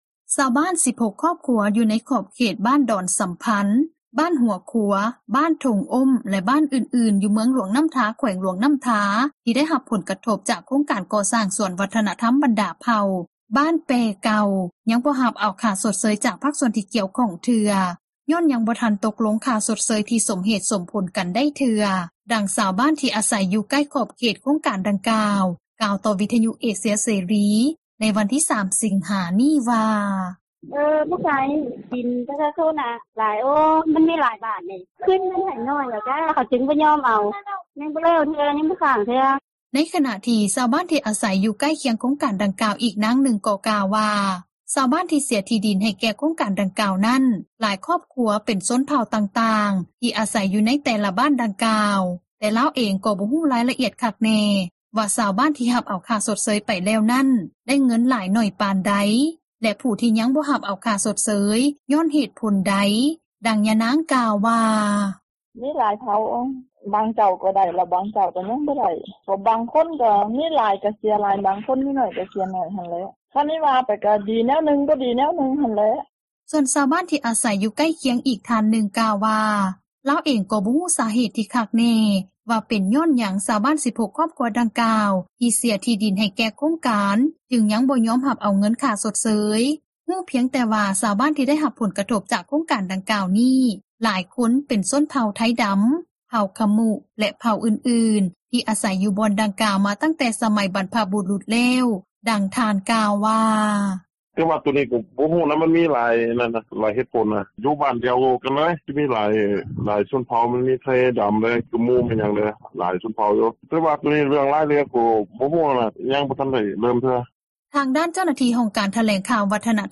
ໃນຂນະທີ່ ຊາວບ້ານທີ່ອາສັຍ ຢູ່ໃກ້ຄຽງໂຄງການດັ່ງກ່າວ ອີກນາງນຶ່ງກໍກ່າວວ່າ ຊາວບ້ານທີ່ເສັຽທີ່ດິນ ໃຫ້ແກ່ໂຄງການດັ່ງກ່າວ ນັ້ນ ຫຼາຍຄອບຄົວເປັນຊົນເຜົ່າຕ່າງໆ ທີ່ອາສັຍຢູ່ ໃນແຕ່ລະບ້ານດັ່ງກ່າວ ແຕ່ລາວເອງ ກໍບໍ່ຮູ້ຣາຍລະອຽດ ຄັກແນ່ ວ່າຊາວບ້ານທີ່ຮັບເອົາ ຄ່າຊົດເຊີຍໄປແລ້ວນັ້ນ ໄດ້ເງິນໜ້ອຍຫຼາຍປານໃດ ແລະ ຜູ້ທີ່ຍັງບໍ່ໄດ້ຮັບເອົາຄ່າຊົດເຊີຍ ຍ້ອນເຫດຜົນແນວໃດ ດັ່ງຍານາງກ່າວວ່າ: